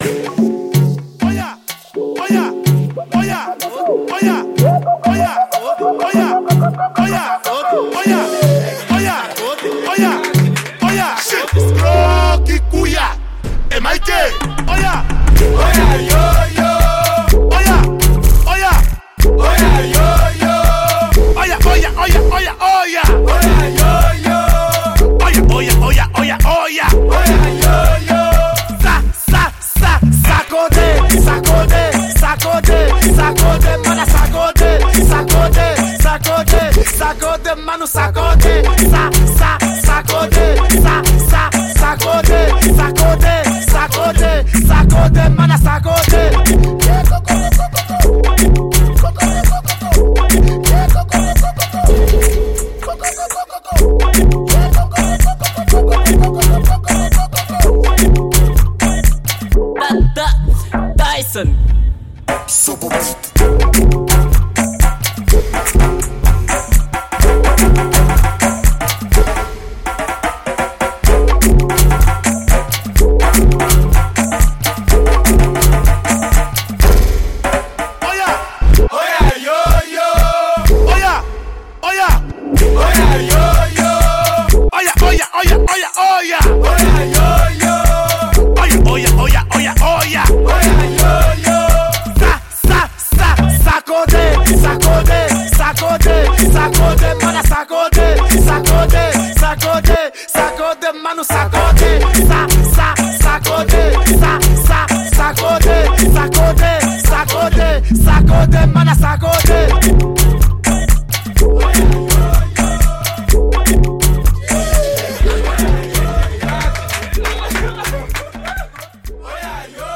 Genero: Kuduro